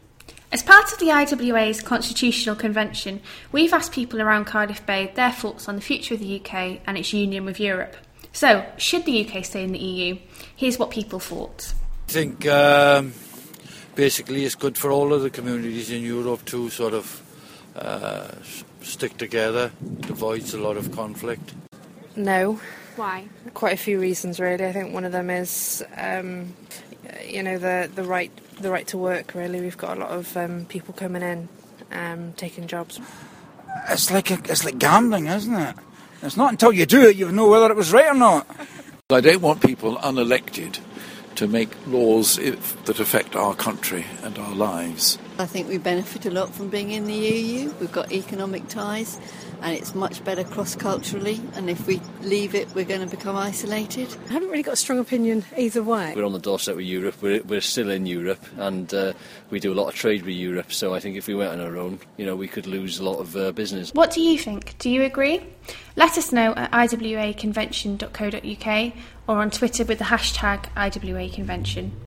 We asked people around Cardiff Bay for their views on Europe and whether the UK should stay in the EU? Here's what they said.